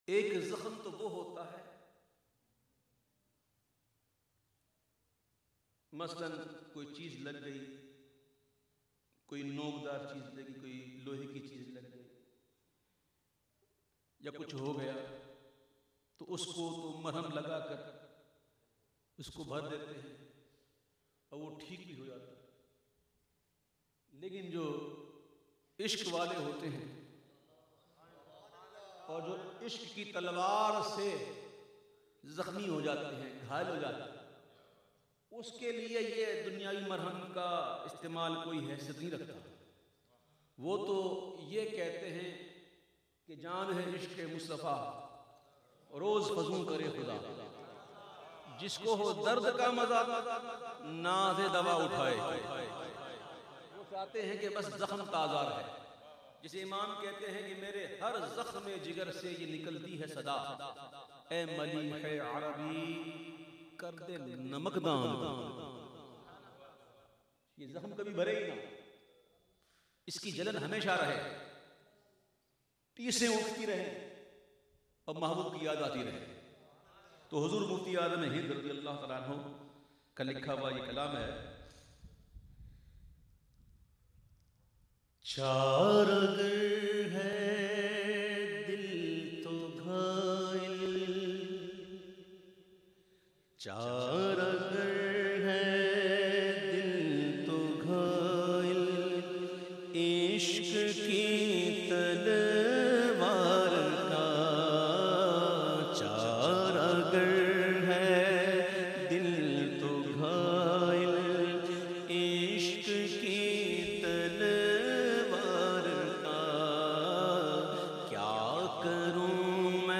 The Naat Sharif Charagar Ha Dil To Ghail recited by famous Naat Khawan of Pakistan Owaise qadri.